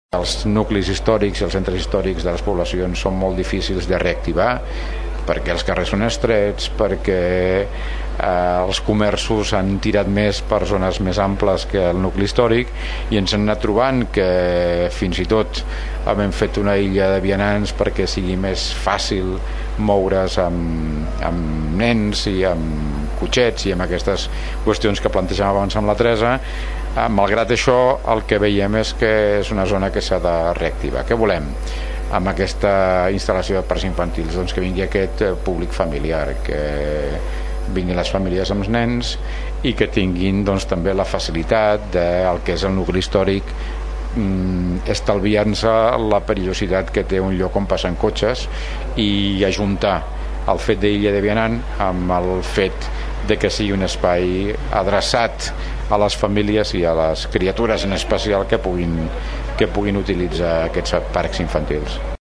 Ho explica l’alcalde de Tordera, Joan Carles Garcia.